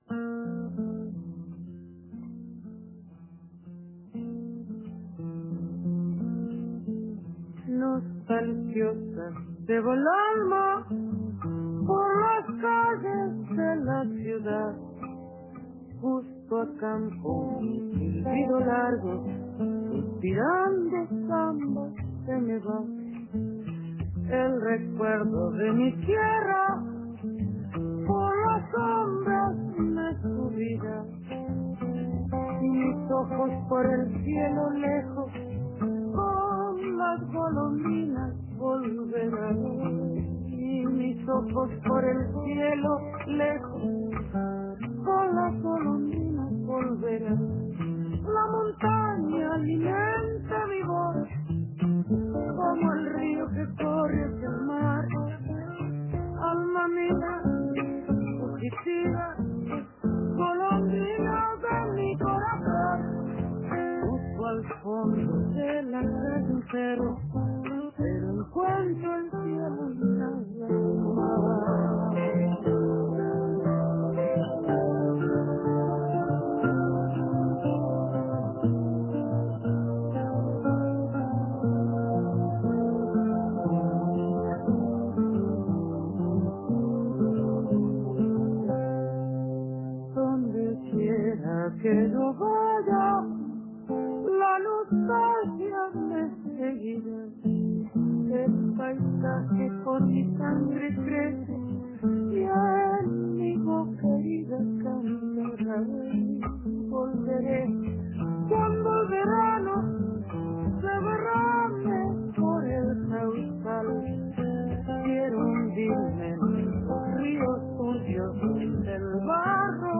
Antes de presentarse por primera vez en Montevideo, la cantante entrerriana Liliana Herrero visitó Planetario